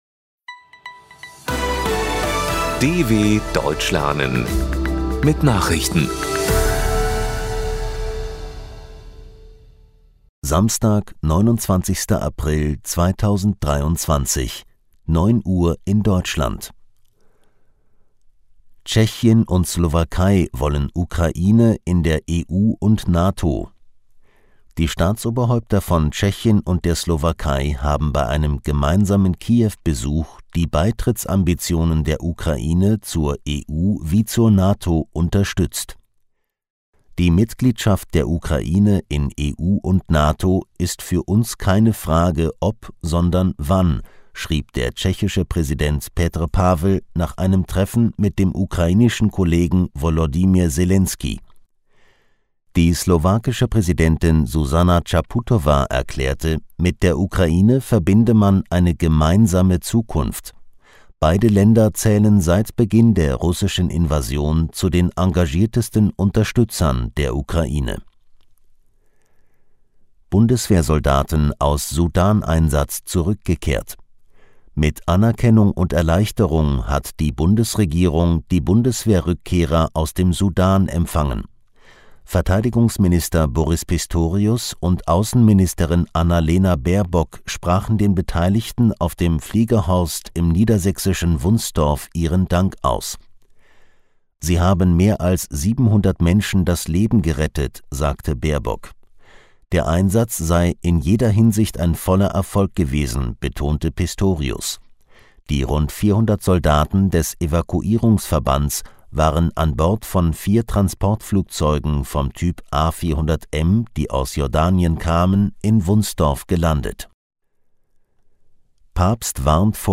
29.04.2023 – Langsam Gesprochene Nachrichten
Trainiere dein Hörverstehen mit den Nachrichten der Deutschen Welle von Samstag – als Text und als verständlich gesprochene Audio-Datei.